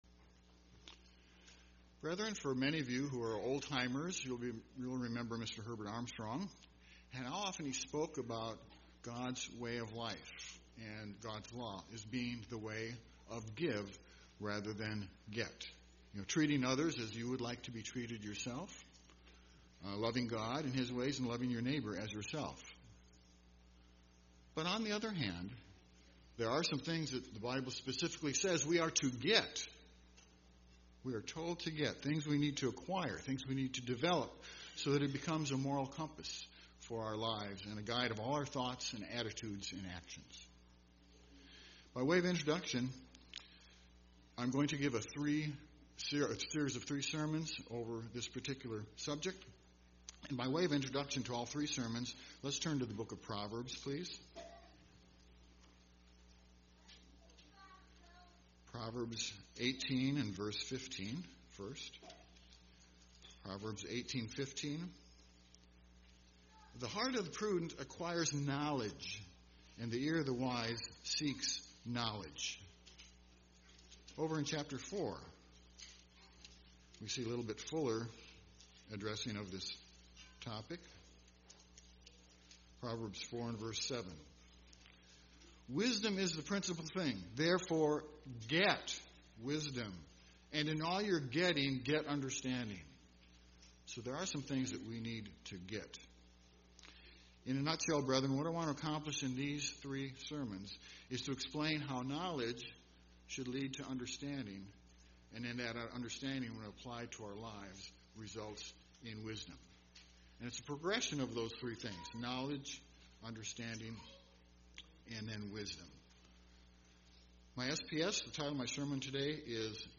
In this set of three sermons, we can see that the Bible instructs us to get knowledge, get understanding, and get wisdom.